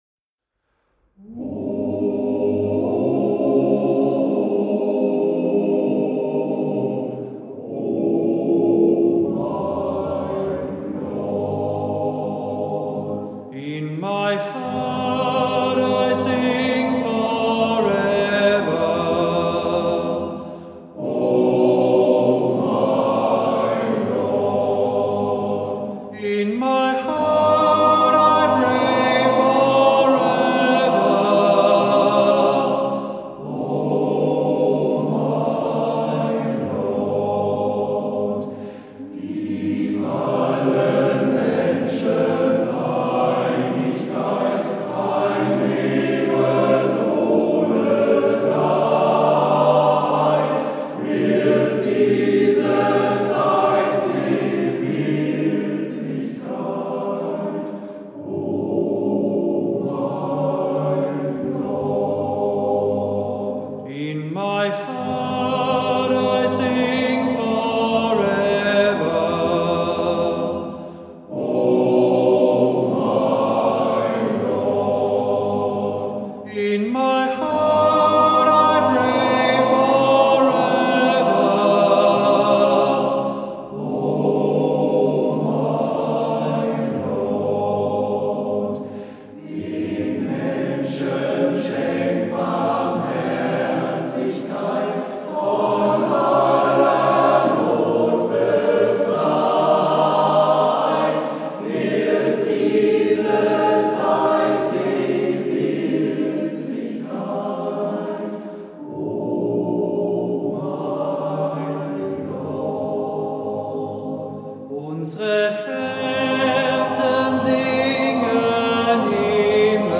Bitte auf diesen Text klicken für weitere Details über die Entstehung der Kapelle: Zur Karte der Stelenstandorte "Oh my Lord" gesungen vom MGV "Cäcila" Volkringhausen e.V. Um das Lied zu hören, bitte auf unser Bild klicken.